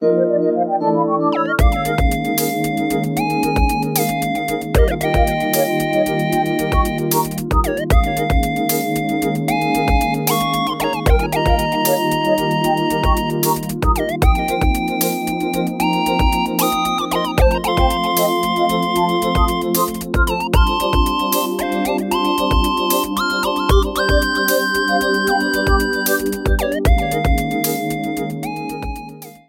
Game rip